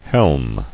[helm]